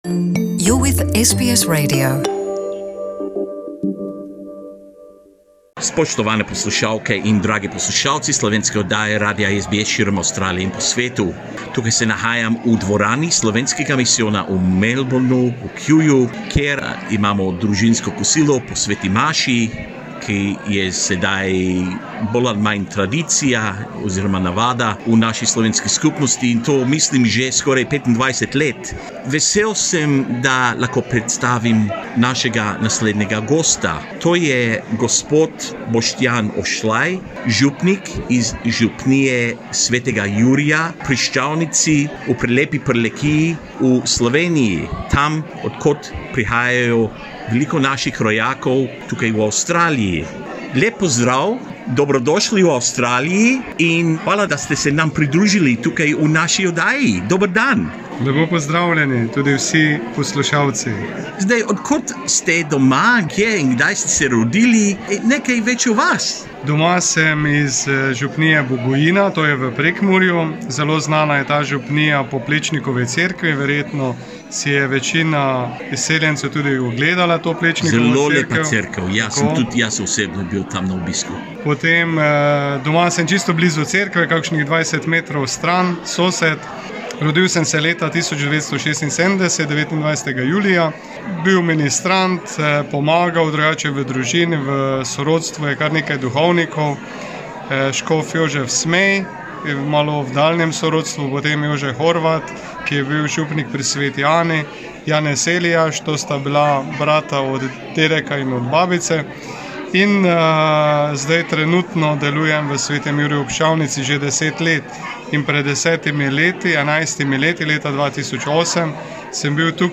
Pridružil se je nam za prijeten pogovor, da smo več izvedeli o njegovem življenju in delu kot duhovnik.